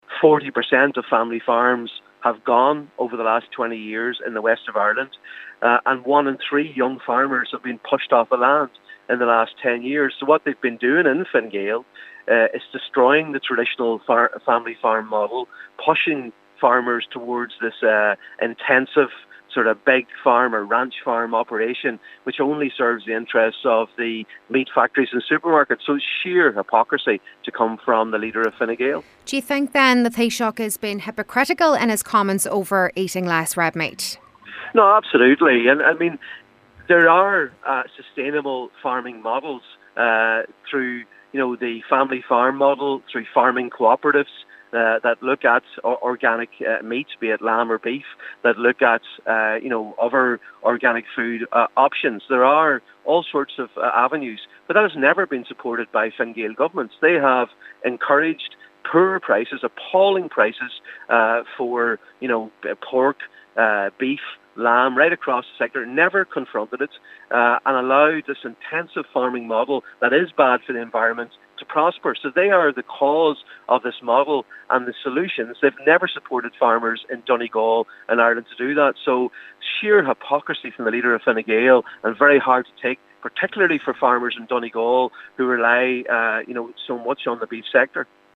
Senator Padraig MacLochlainn says given Fine Gaels approach to the industry over the years, the Taoiseach is being hypocritical and he should be trying to support struggling farming families instead: